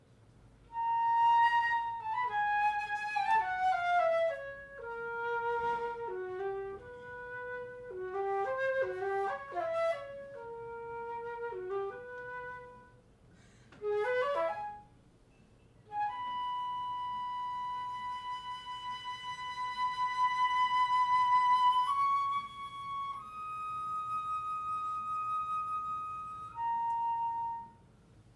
Solo
Extrait lors d’une lecture & concert, Gazette Café, Montpellier, juin 2023